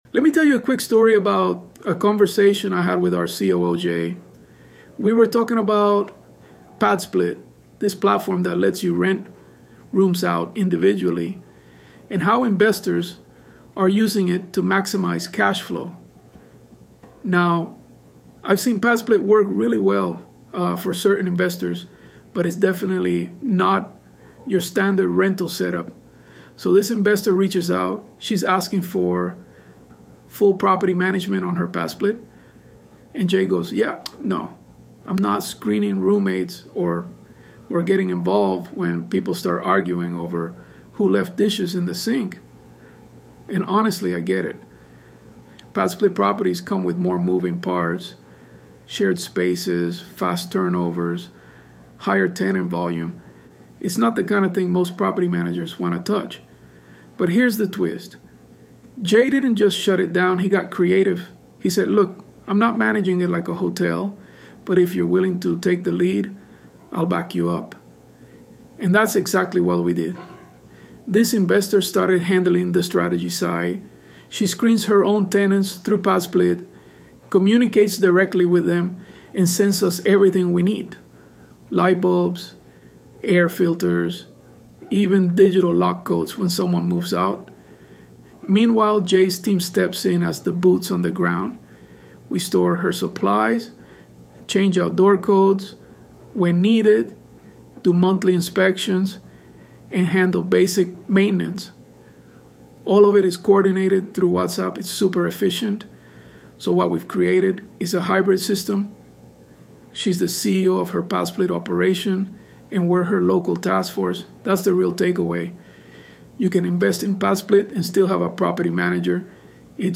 ElevenLabs_Untitled_Project-2.mp3